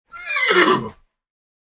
Horse Sound Effect Neighing
Short, isolated sound of a horse whinnying in a stable. Farm animal sounds.
Horse-sound-effect-neighing.mp3